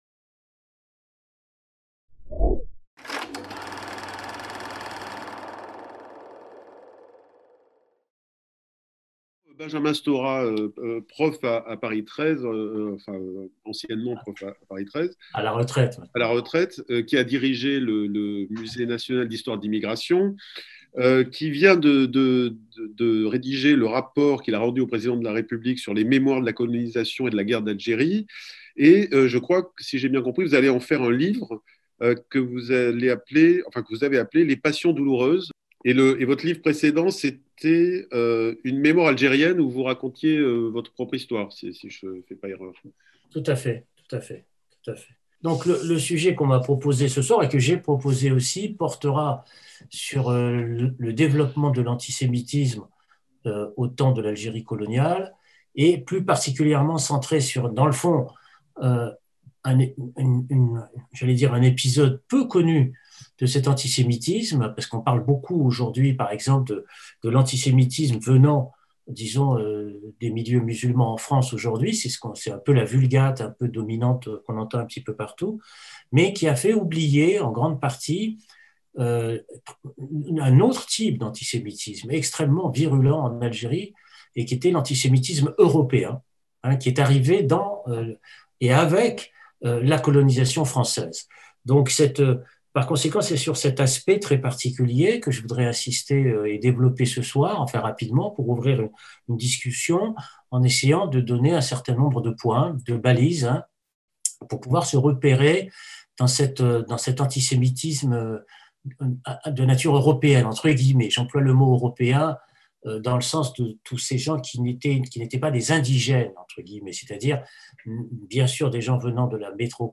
Séminaire Racisme et Antisémitisme | Mercredi 17 février